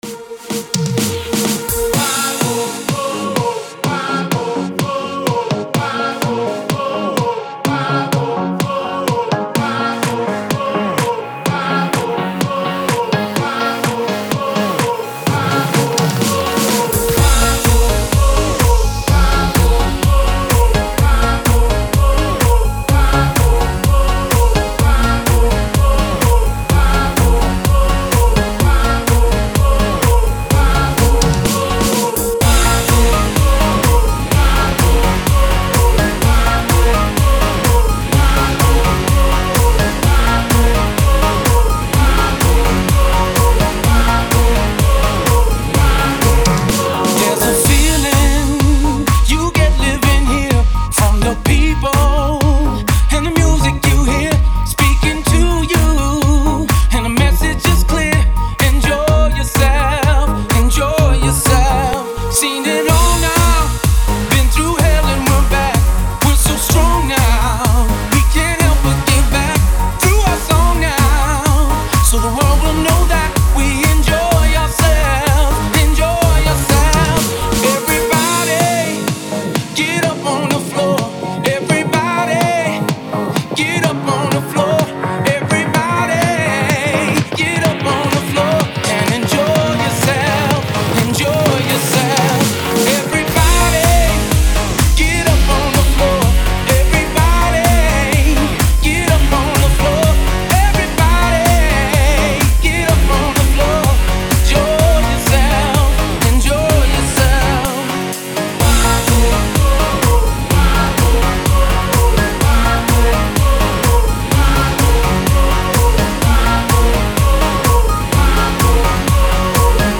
Категория: Клубная музыка